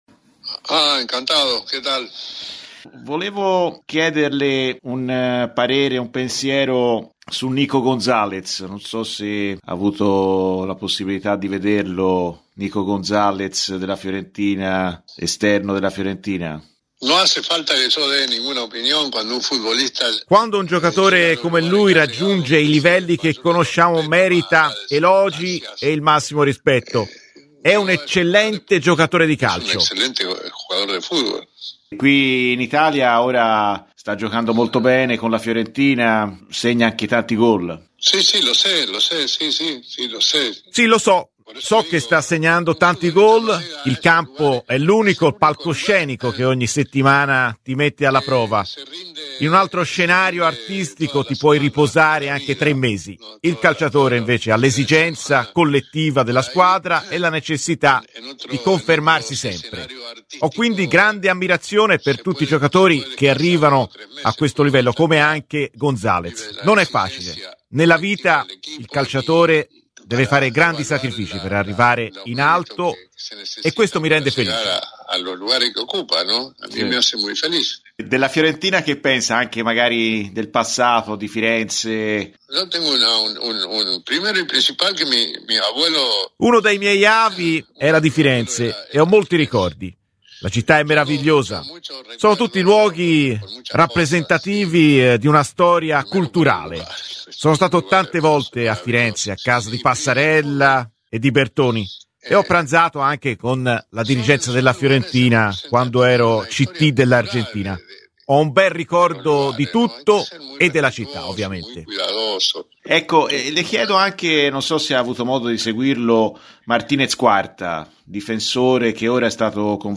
L'attuale dirigente della Nazionale argentina, ed allenatore dell'"albiceleste" campione del mondo nel 1978, "El Flaco" Cesar Luis Menotti è stato intervistato da Radio Firenzeviola.